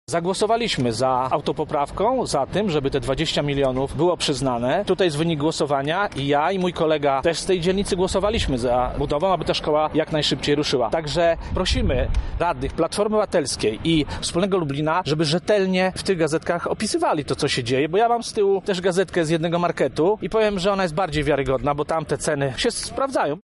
„To dzięki radnym Prawa i Sprawiedliwości ta budowa mogła ruszyć”, mówi miejski radny Zbigniew Ławniczak